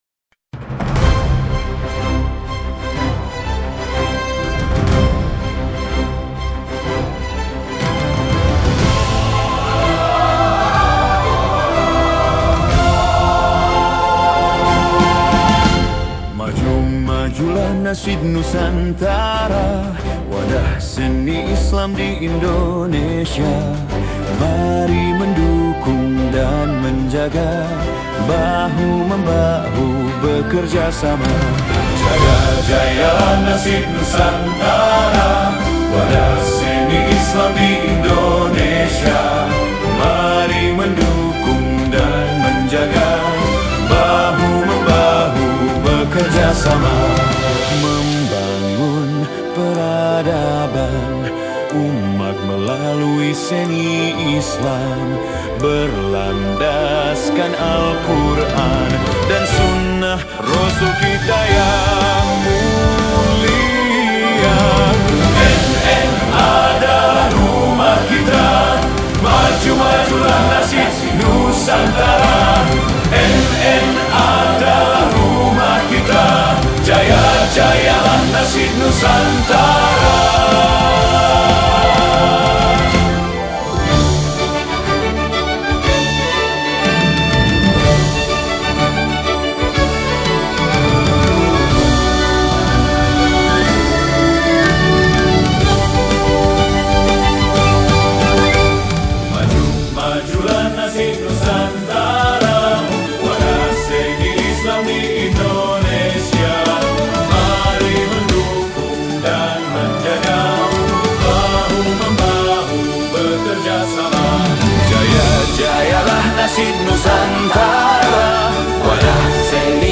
🎵 Lagu Kebanggaan